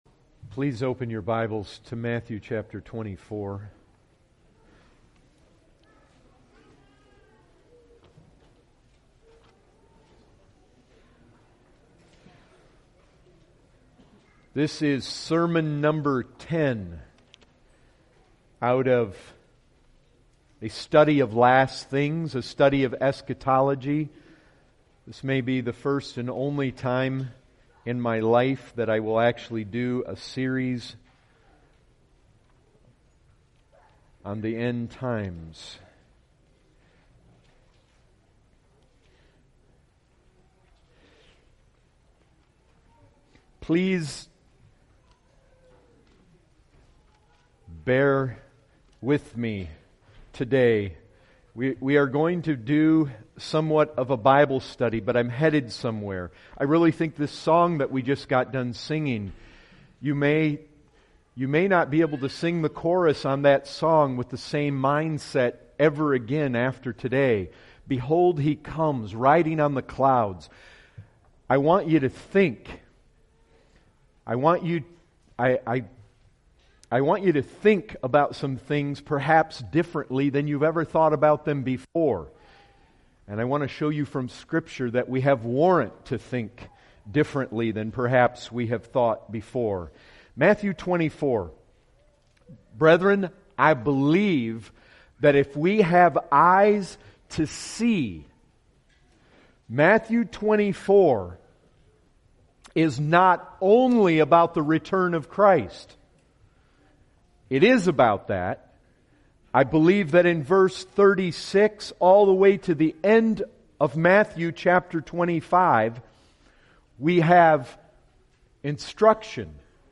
2015 Category: Full Sermons Topic